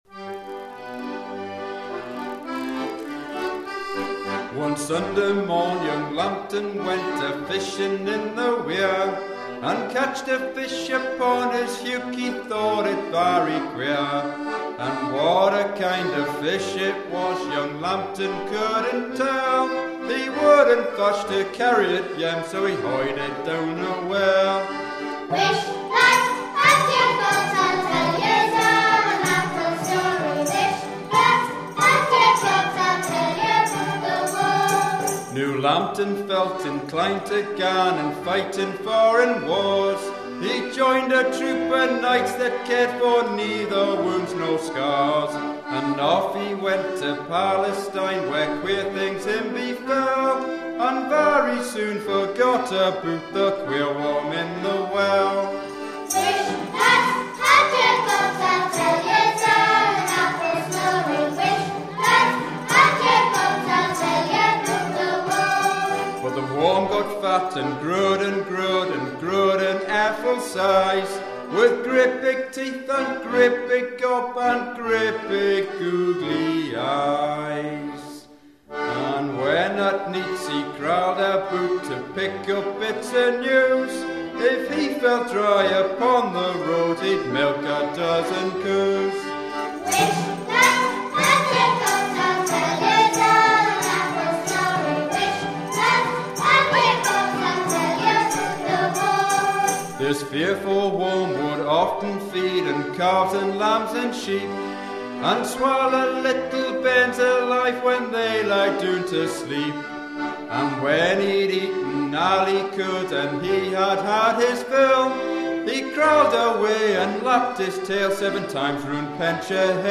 There are four versions of the story: Northumbrian dialect, Geordie dialect, Standard English and a sung version.
Stobhillgate Ceilidh Band
Stobhillgate First School Ceilidh Band
The band sing and accompany themselves in their own inimitable style!